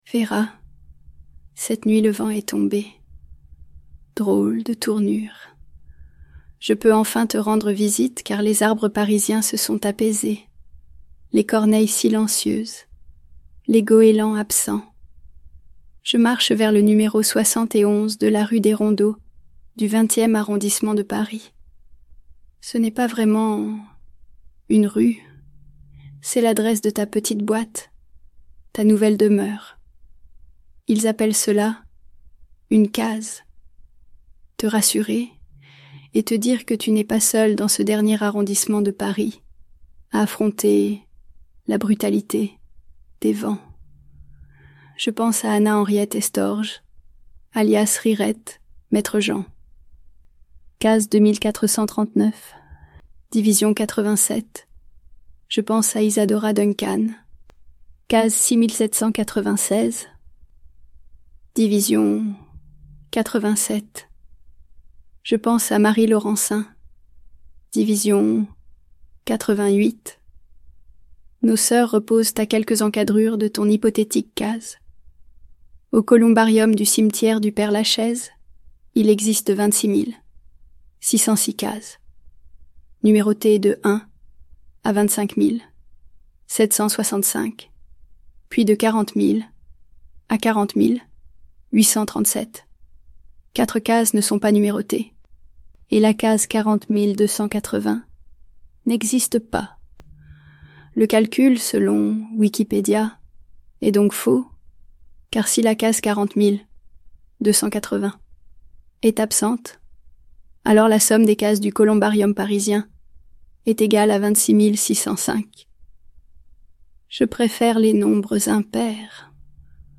Variation papier 42 x 29,7 cm - recto verso Variation sonore Format audio MP3 Voix générée par IA à partir d'échantillonnages de la pièce Alissa IA. Une série de 16 lettres issues de l'enquête composent le récit linéaire de La timidité des cimes - de Paris à Budapest, jusqu'à la plage d'inspiration de Vera Molnár, le Hôme-Varaville.